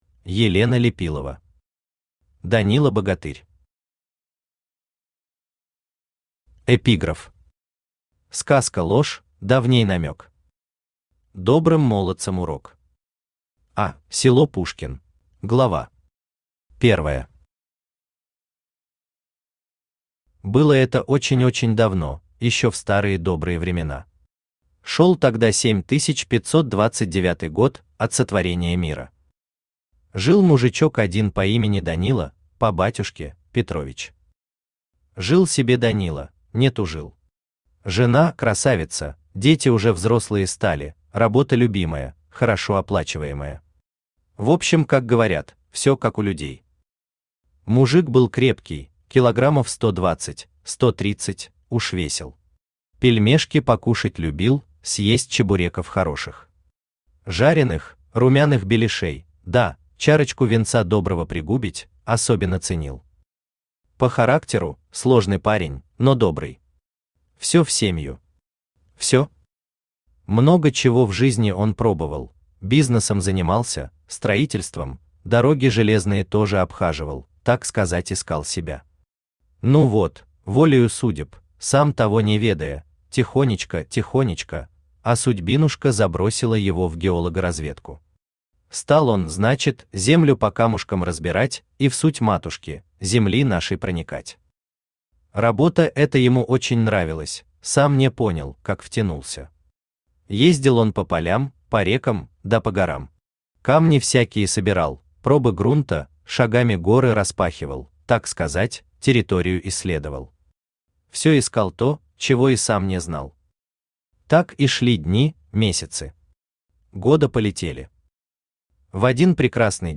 Аудиокнига Данила-богатырь | Библиотека аудиокниг
Aудиокнига Данила-богатырь Автор Елена Лепилова Читает аудиокнигу Авточтец ЛитРес.